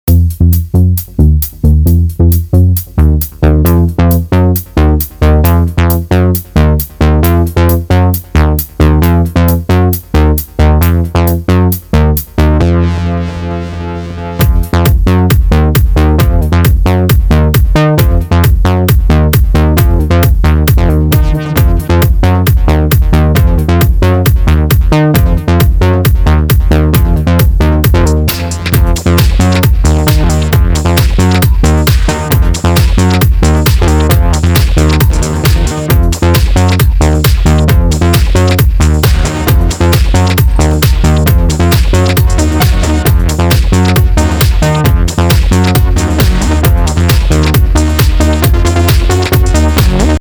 I had some SSM2044 stereo filter fun:
In the second demo I combined ENV (with exponential curve!) and LFO through the MOD matrix to produce different stereo effects while a simple bassline is playing:
Yes, for all demos I used the Kraftzwerg VCO.